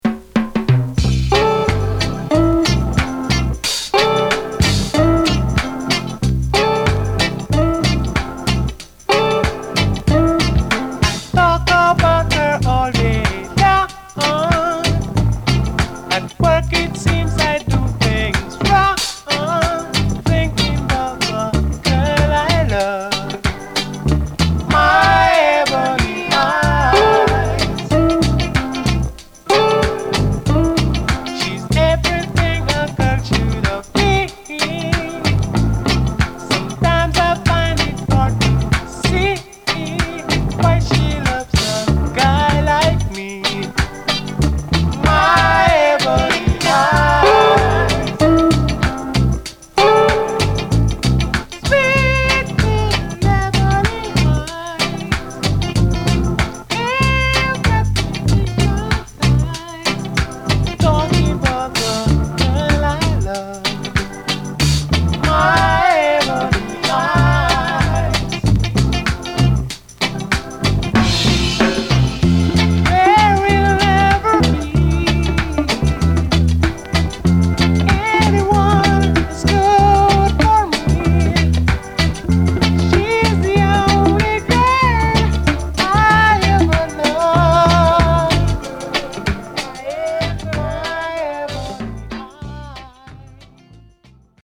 原曲の雰囲気を残したスマイリーで陽気なアレンジでナイス・カヴァー！